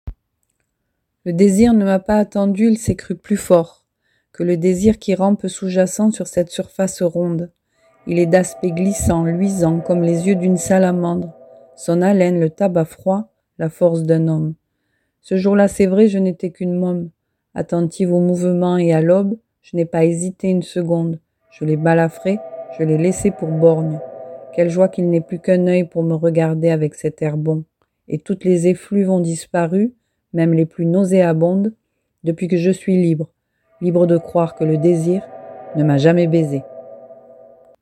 Bandes-son
- Contralto Mezzo-soprano